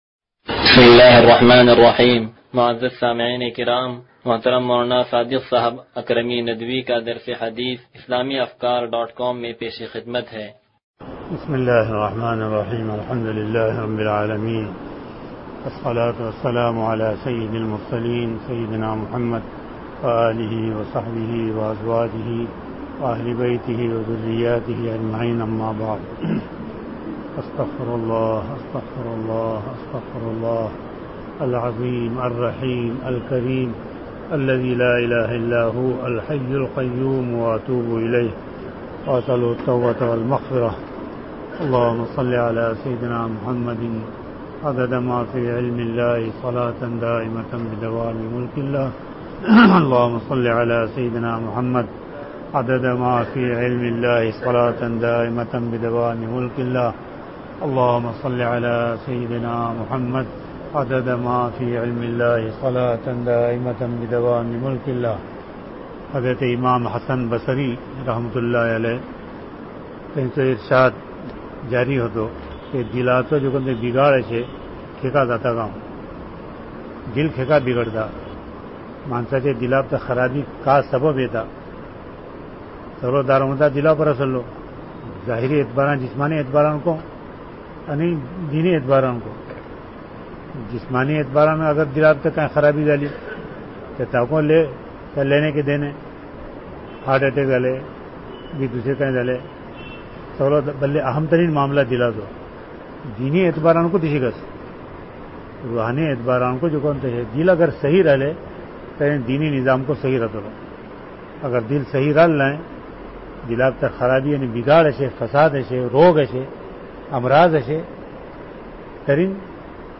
درس حدیث نمبر 0168